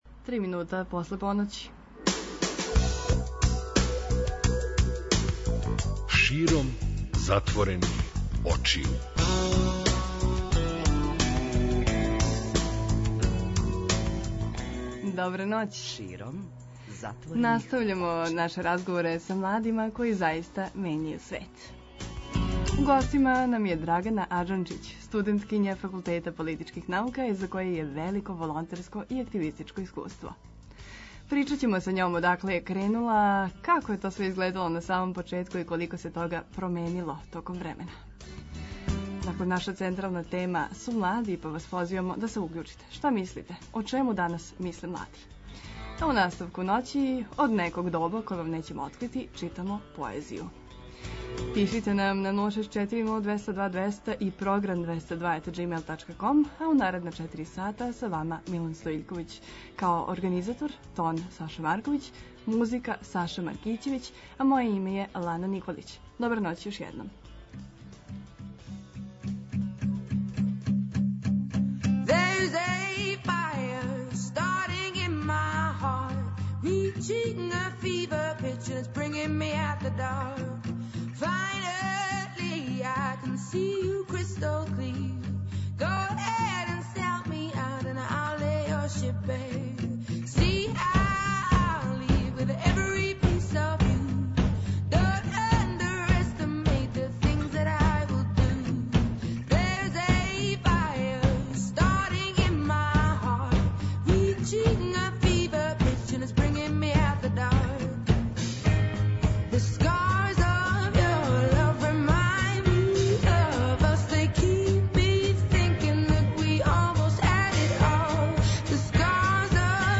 У наставку ноћи читамо поезију.